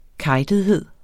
Udtale [ ˈkɑjdəðˌheðˀ ]